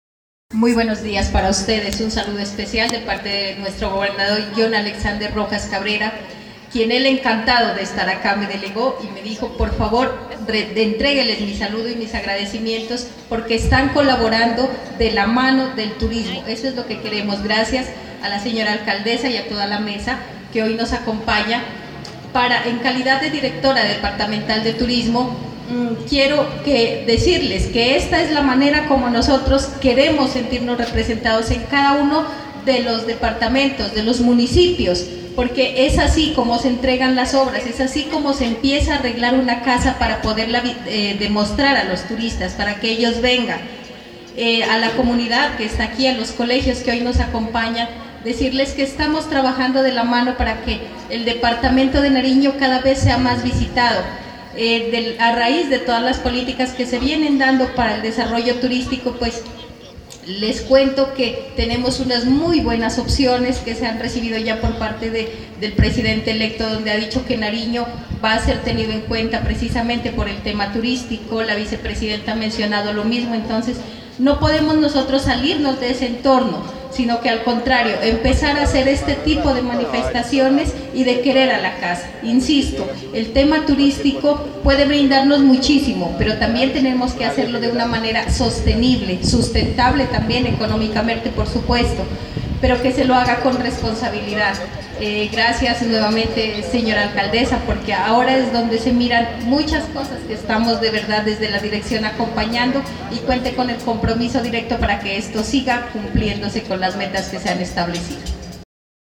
En el parque de la vereda Ingenio Centro este martes en la mañana se realizó el lanzamiento del programa “Sandoná pueblo que enamora, con arte pintura y color”.